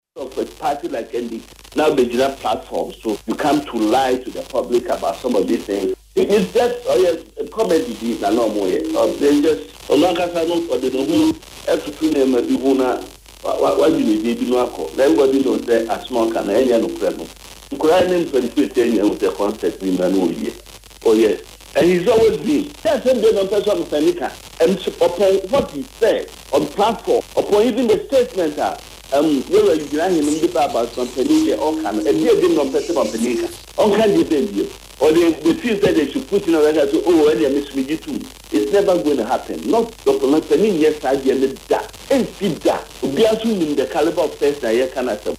“I see Asiedu Nketia as a comedian because he always wants to joke with even serious issues and that does not help our economy. To me, anything he says is senseless. He has always been a comedian and it’s time he sits up and acts as a politician and a general secretary of a political party”, he said on Accra-based Neat FM.